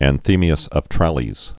(ăn-thēmē-əs; trălēz) fl. sixth century AD.